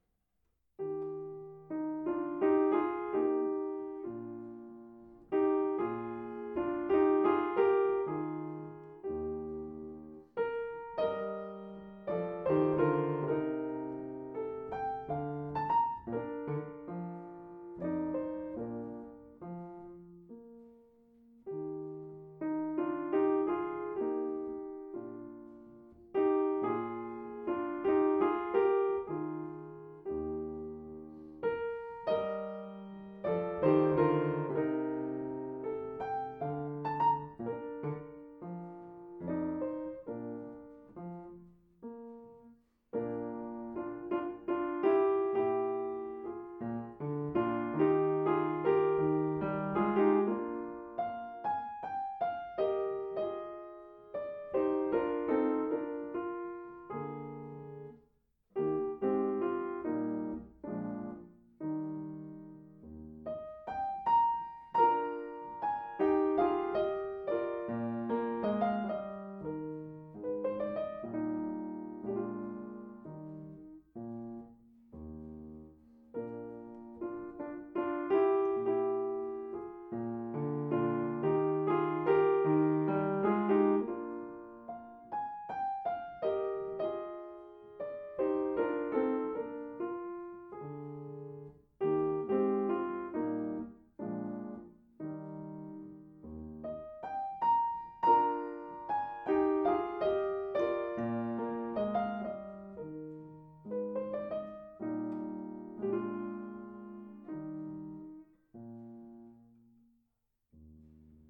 La obra para piano solo de Mozart, conocida generalmente por sus 18 sonatas, 16 ciclos de variaciones y 4 Fantasías, consta además de un importante cuerpo de piezas sueltas compuestas a lo largo de su corta vida.